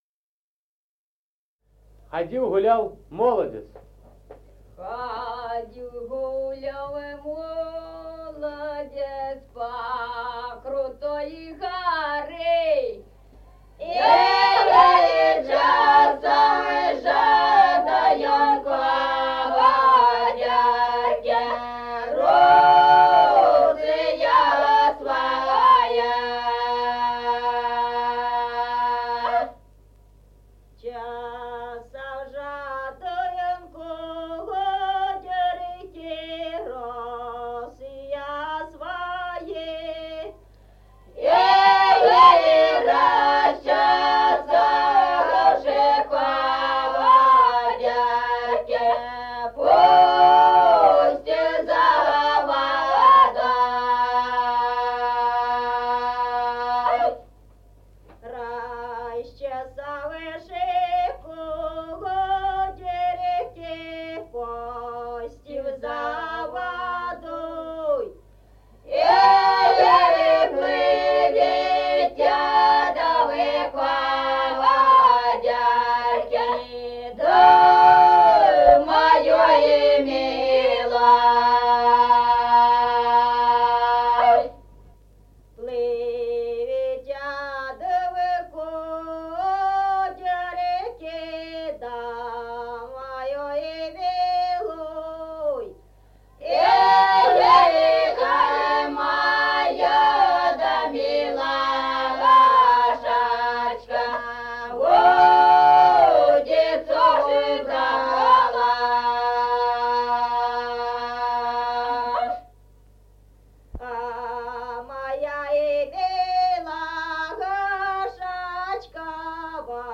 Музыкальный фольклор села Мишковка «Ходил, гулял молодец», лирическая.